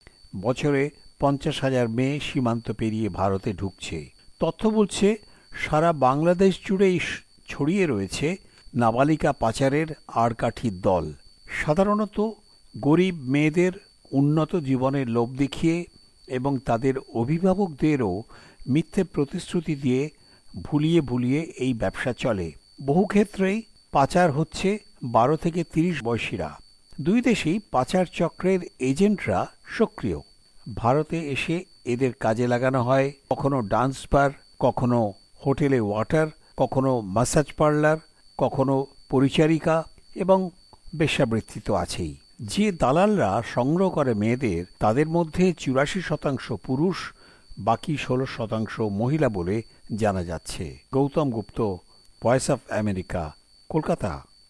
প্রতিবেদন।